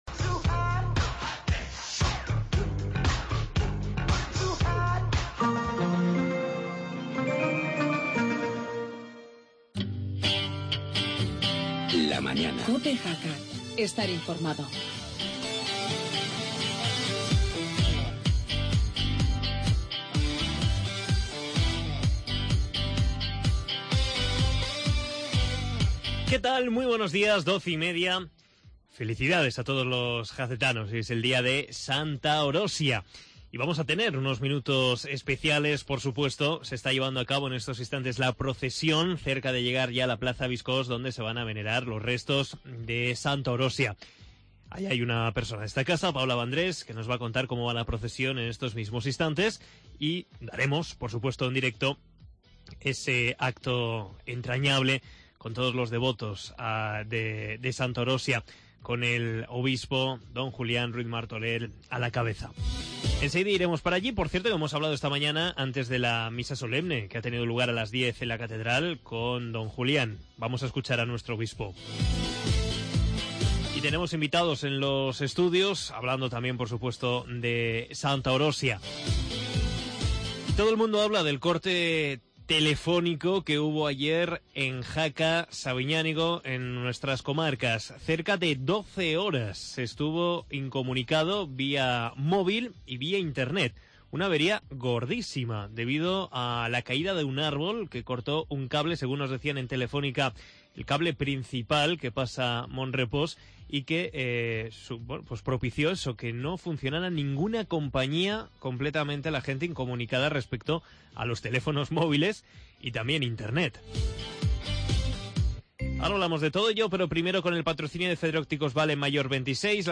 entrevista al obispo Don Julian Ruiz Martorel
conexiones en directo con la plaza Biscos